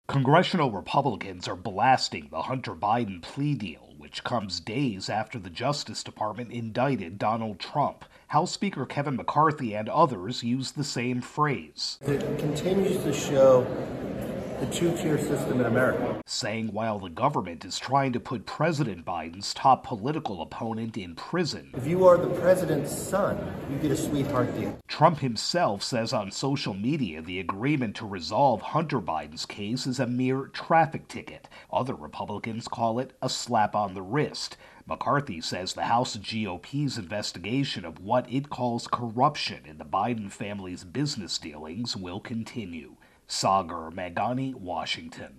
reports on Hunter Biden-McCarthy reaction